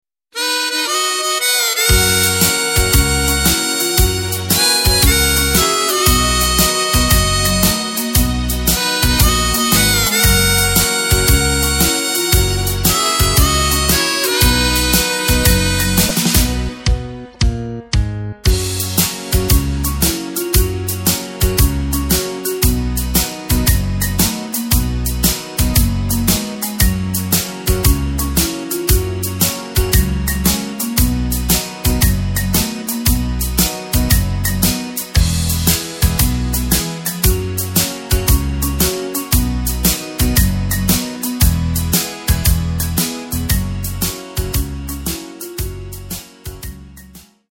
Takt:          4/4
Tempo:         112.50
Tonart:            B
Schlager aus dem Jahr 1974!
mp3 Playback mit Lyrics